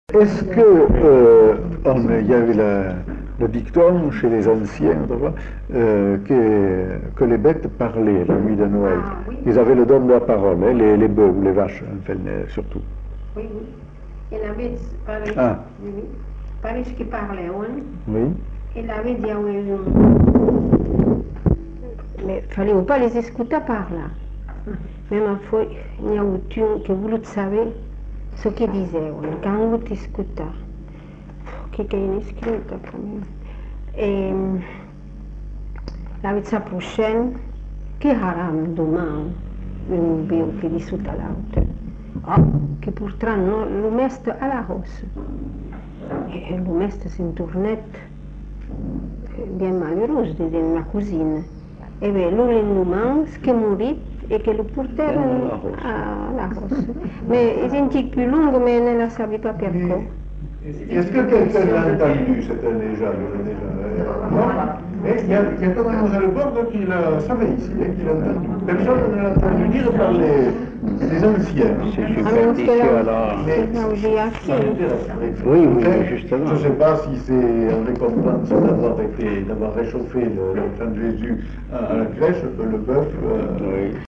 Lieu : Bazas
Genre : conte-légende-récit
Type de voix : voix de femme Production du son : parlé